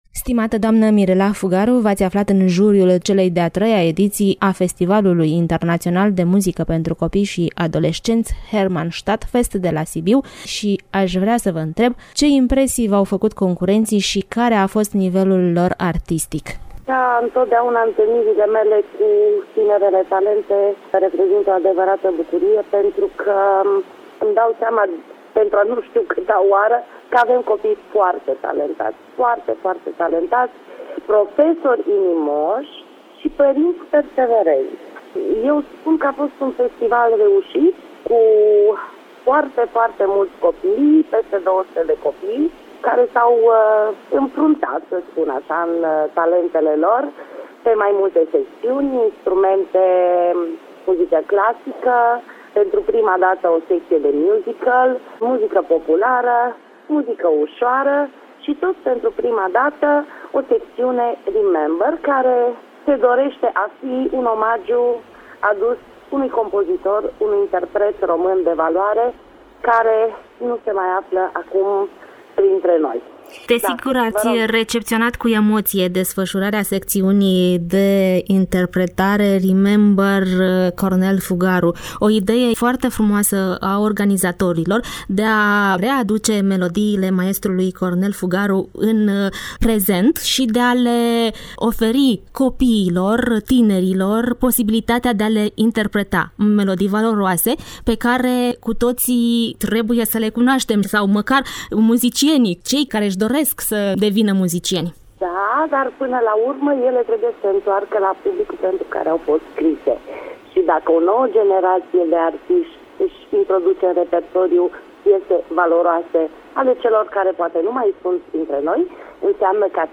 a contactat-o telefonic